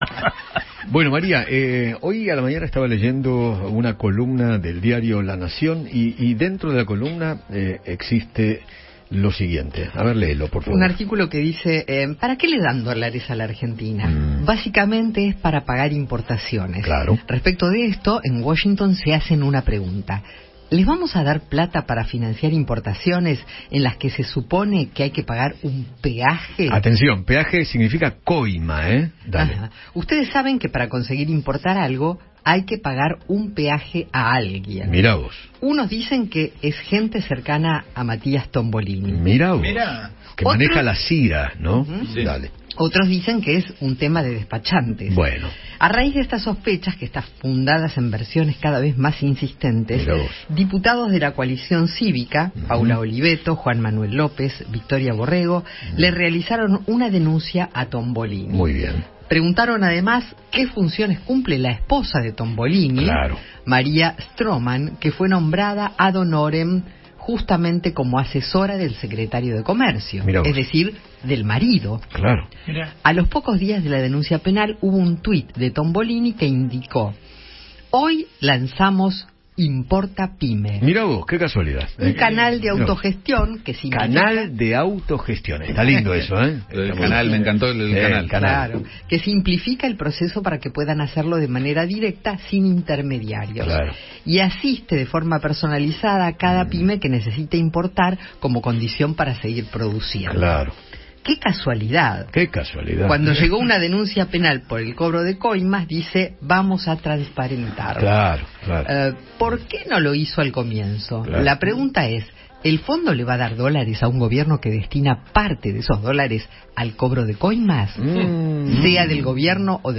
Paula Oliveto, diputada nacional de Juntos por el Cambio, dialogó con Eduardo Feinmann sobre el petitorio que presentó a la Justicia para que se investiguen las sospechas por corrupción que difundió el Gobierno.